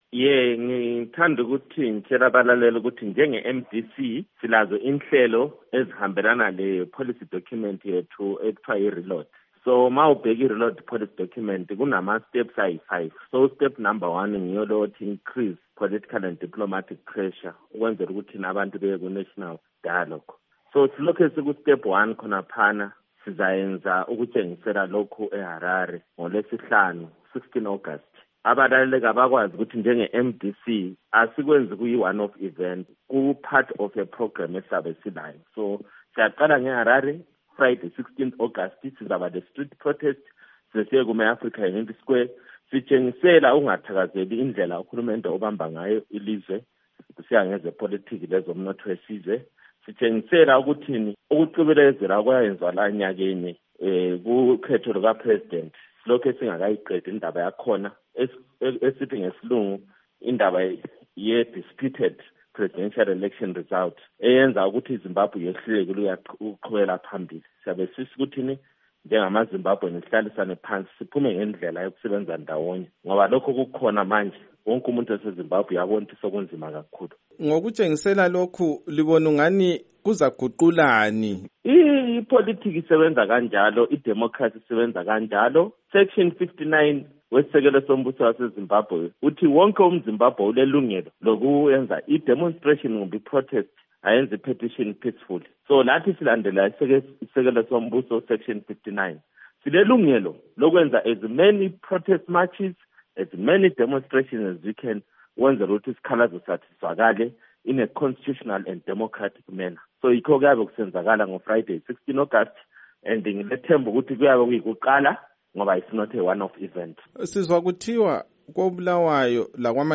Ingxoxo loMnu. Daniel Molokele